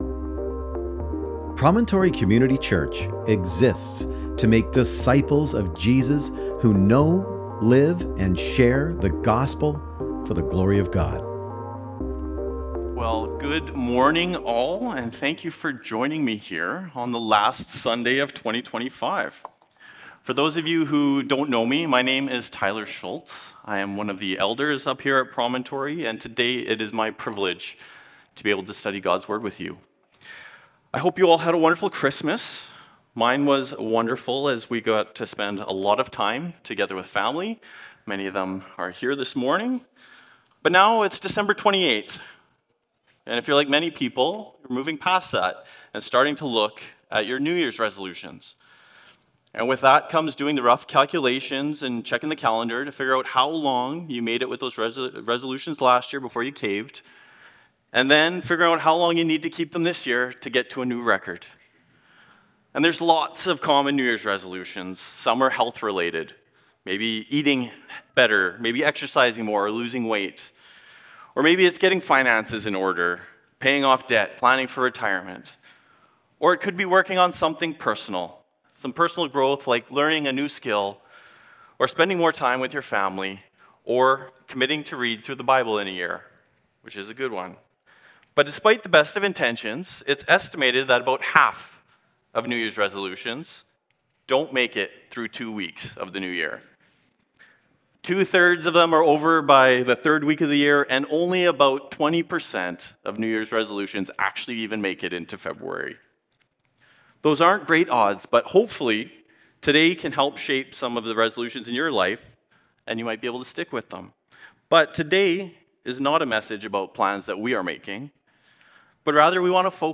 Sermon Transcript: (transcribed with AI) Well, good morning all, and thank you for joining me here on the last Sunday of 2025.